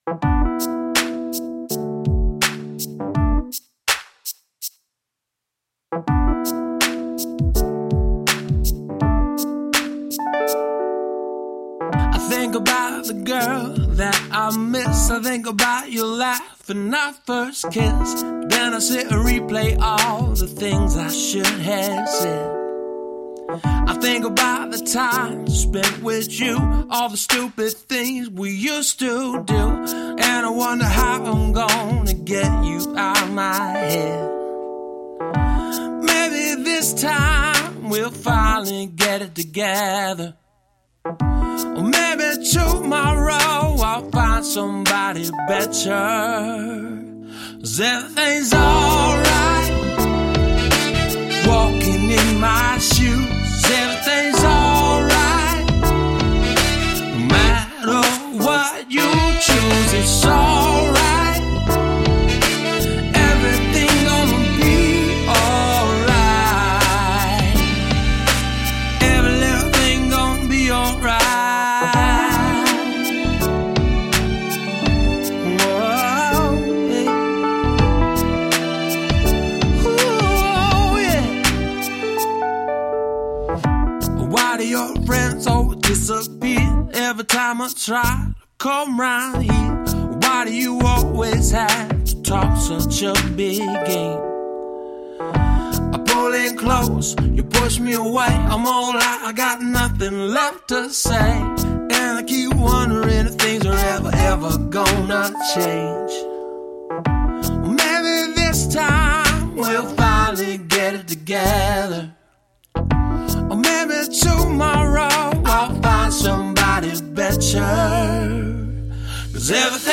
Singer songwriter with a little soul and grit.
Electric Guitars
Acoustic Guitars
Extra Percussion